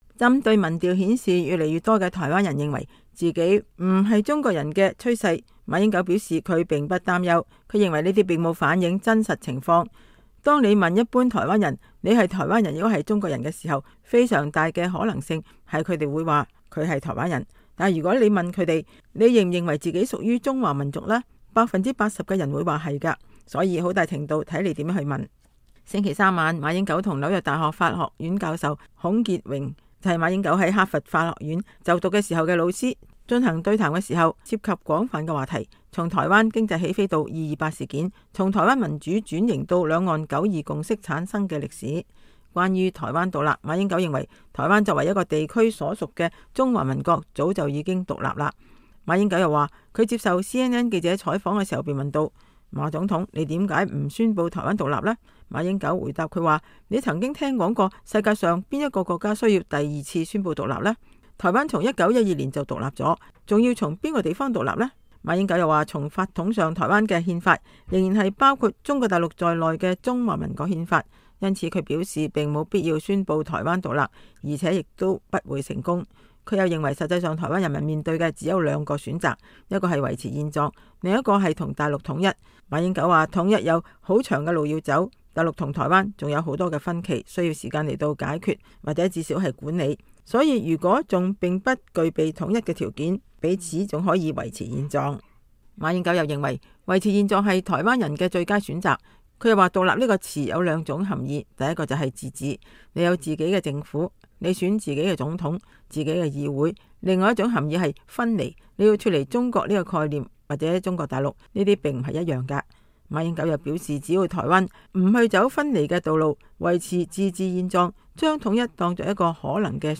星期三，亞洲協會的大會場內座無虛席。